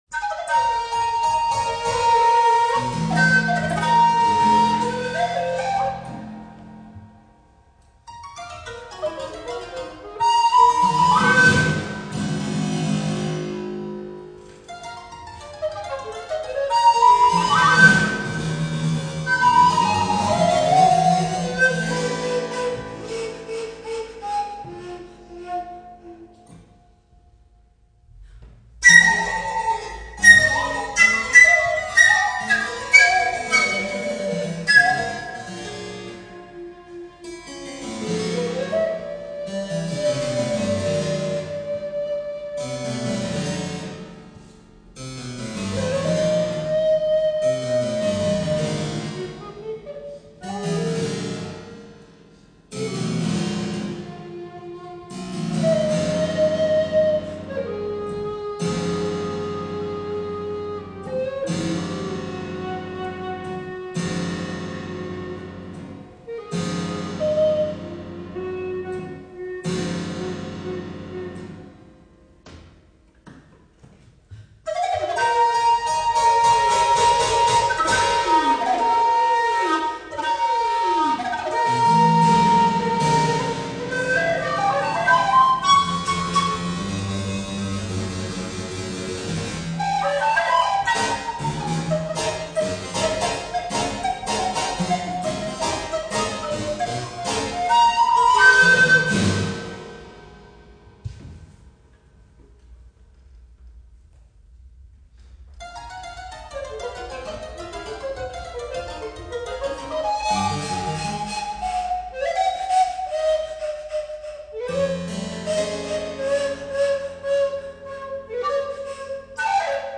for alto recorder and harpsichord (2003)
alto recorder
harpsichord